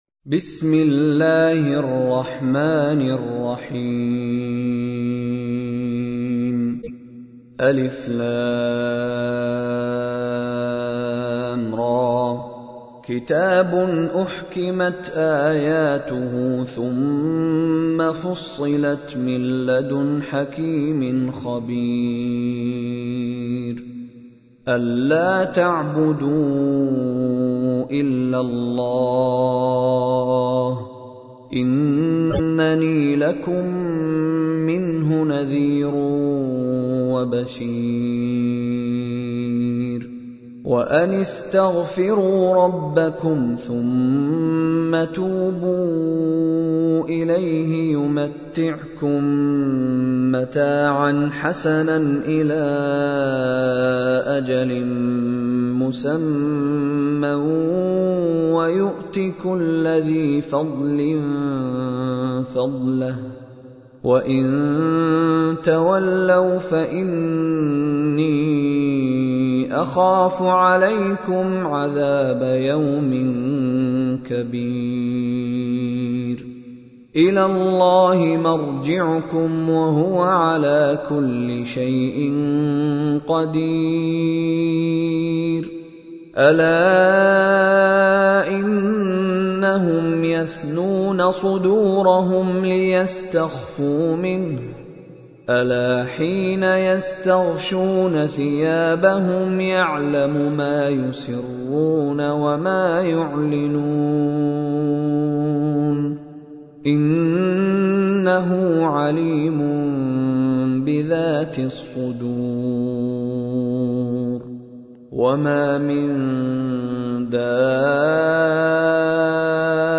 تحميل : 11. سورة هود / القارئ مشاري راشد العفاسي / القرآن الكريم / موقع يا حسين